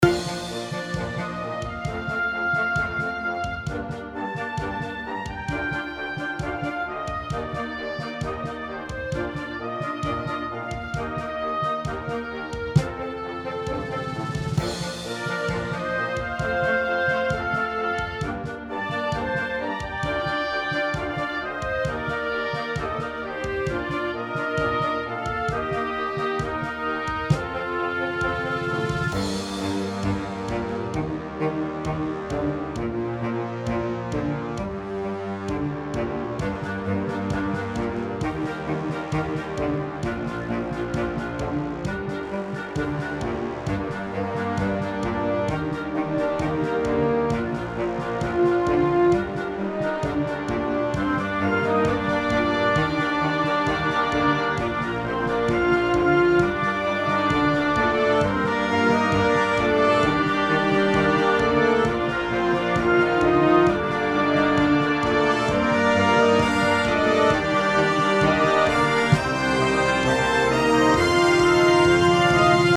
This is in the key of Bb major and transitions to G minor.
brass quartet